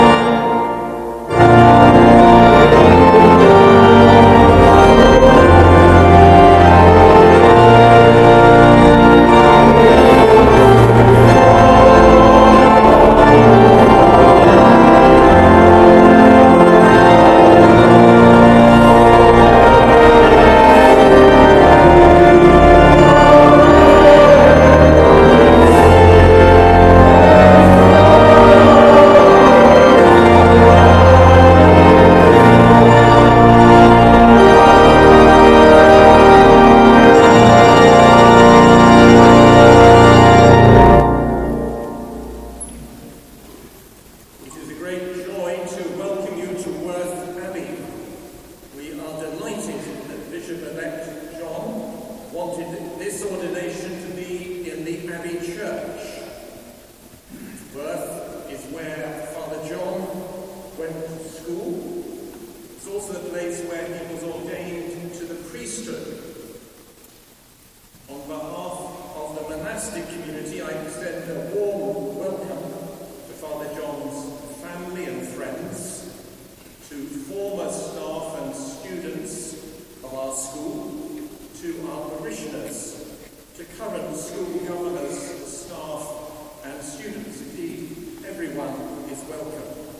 ORDINATION EPISCOPALE de Mgr John MacWilliam
Monastère bénédictin de Worth Abbey
Hymne d' entrée et Accueil
Entrance Hymn